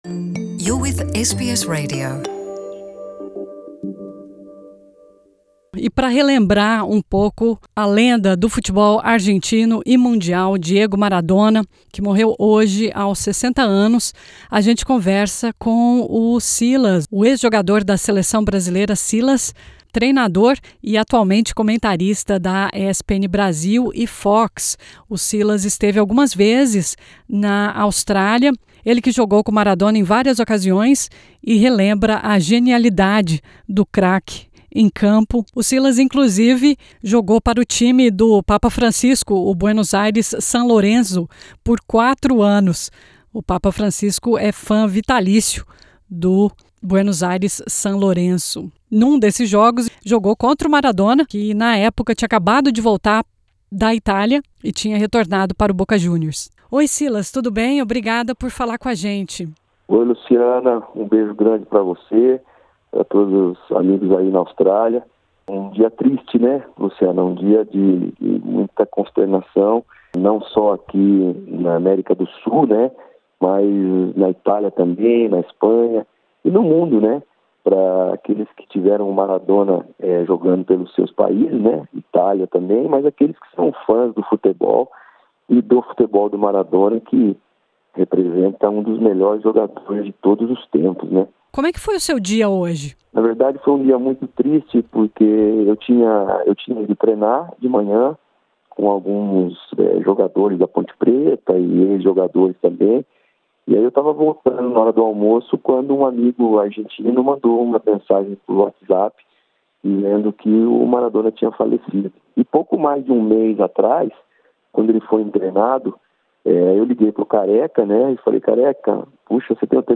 Silas, ex-jogador da seleção brasileira, ex-craque do Buenos Aires San Lorenzo, treinador e atualmente comentarista da ESPN Brasil e Fox, jogou com Maradona e relembra a genialidade de ‘Diego’ em campo.